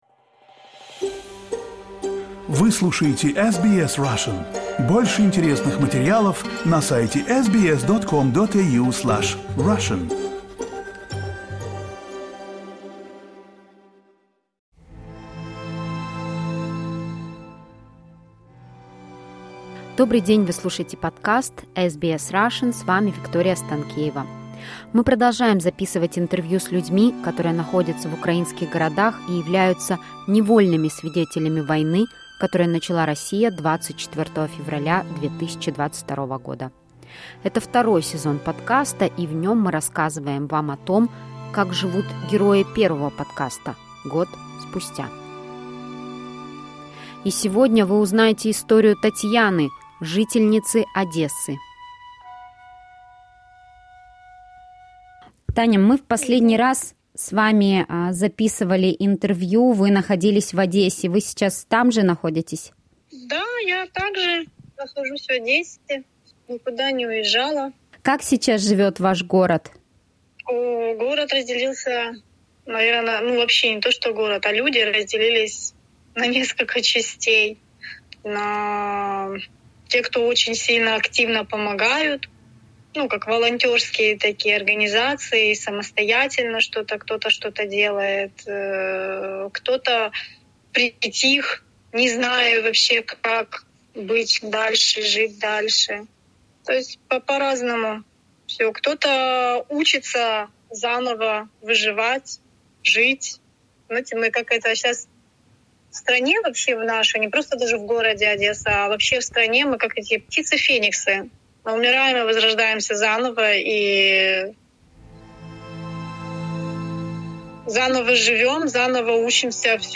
Мы продолжаем записывать интервью с людьми, которые находятся в украинских городах и являются невольными свидетелями войны, которую начала Россия 24 февраля 2022 года.